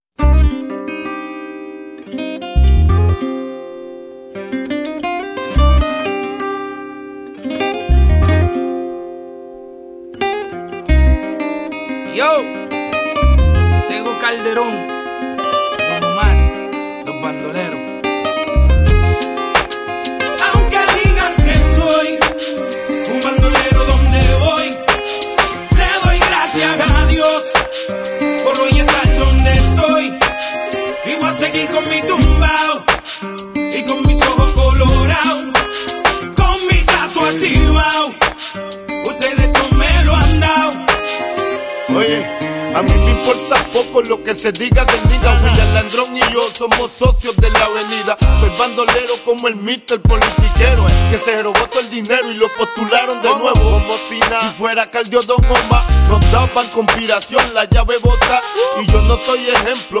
Drag Race Vs BMW ☺ sound effects free download